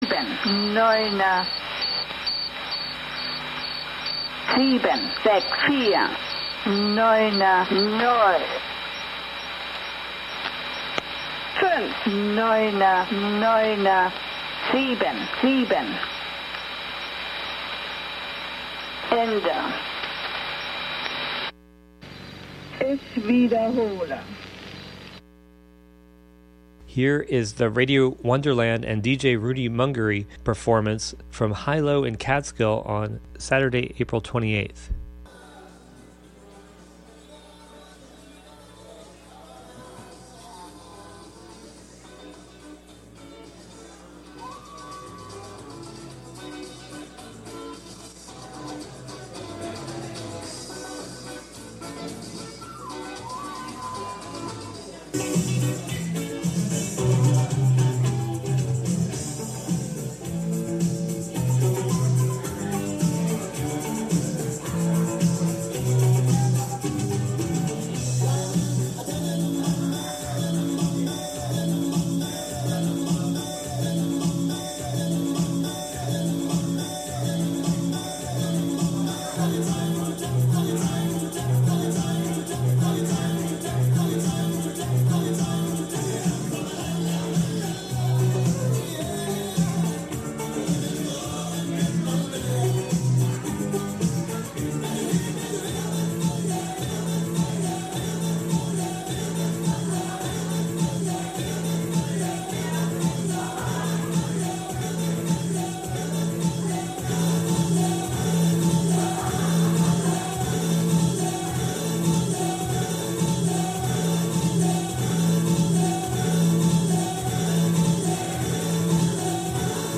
11am Live from Brooklyn, New York
making instant techno 90% of the time